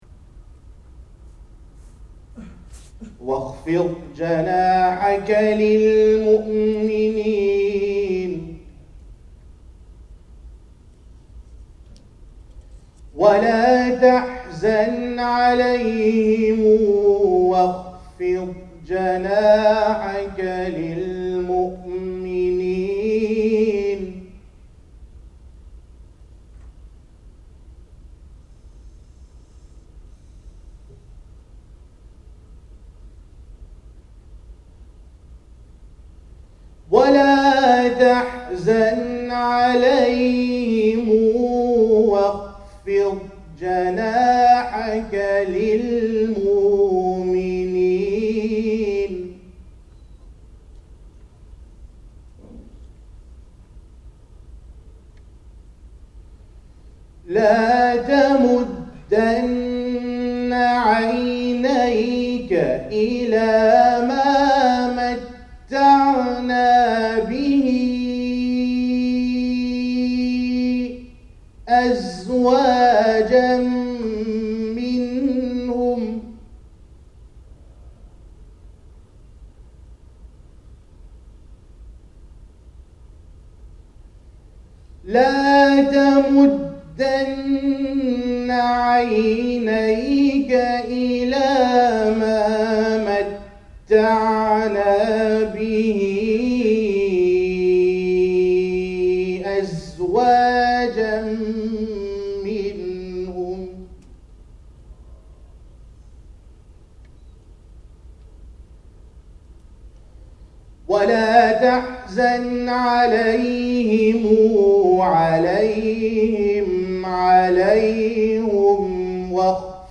Only those fortunate enough to be present and awake during our post-fajr tajweed sessions at Ilm Summit 2010 know the story behind the recitation of these verses.
surah-hijr-alaq-in-multiple-qiraat.mp3